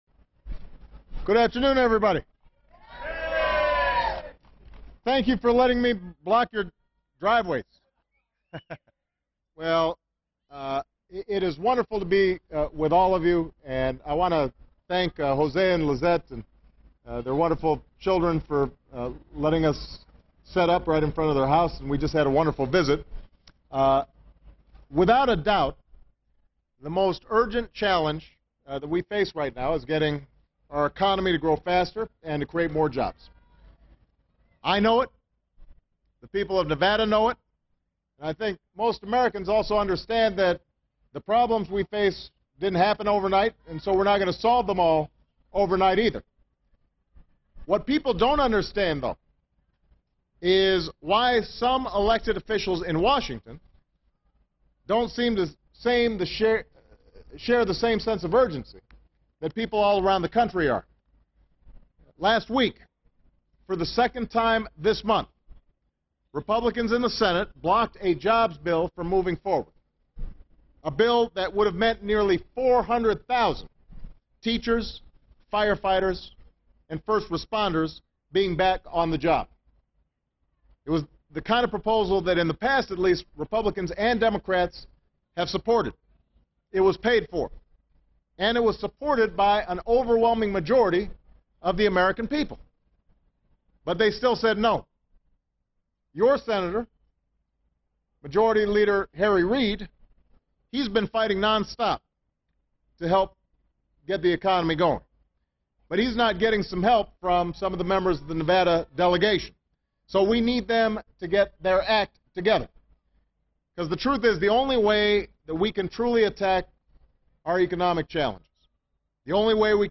President Barack Obama speaks to homeowners in Las Vegas, NV about changes to the Home Affordable Refinance Program (HARP). He says he will ask Freddie Mac and Fannie Mae to loosen HARP restrictions, making it easier for property owners to refinance their home loans even if the market value of the home has dropped below the loan balance.